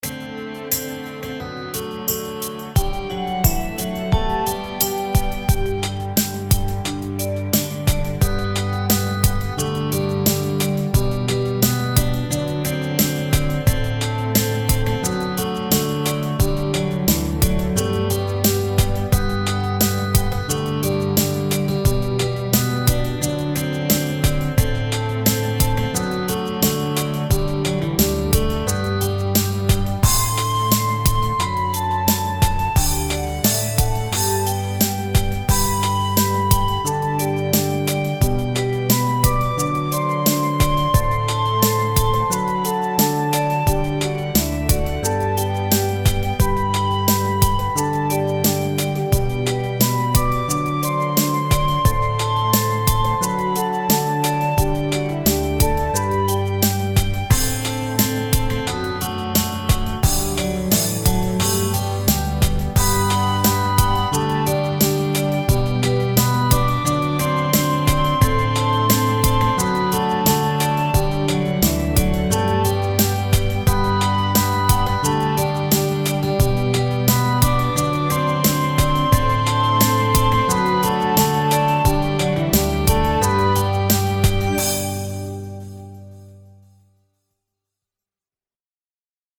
Karaoke Songs for Kids
instrumental, 1:29 – 4/4 – 88 bpm
Type: Traditional nursery rhyme / counting rhyme